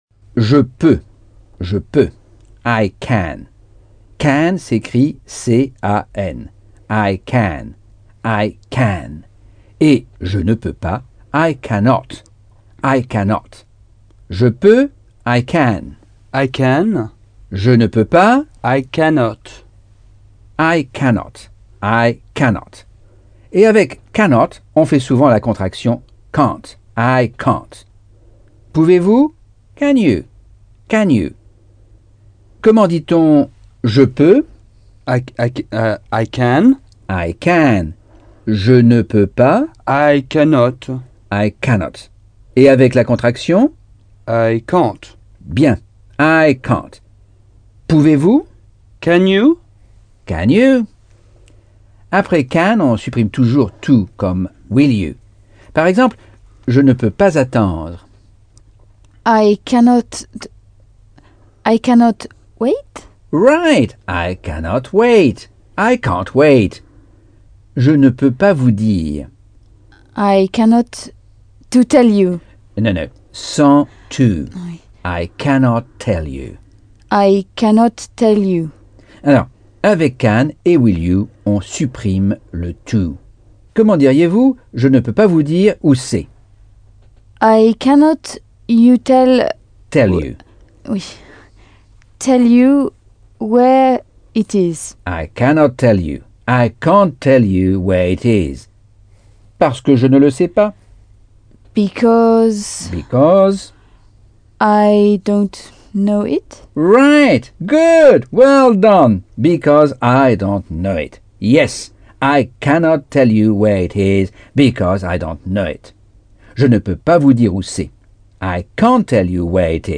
Leçon 9 - Cours audio Anglais par Michel Thomas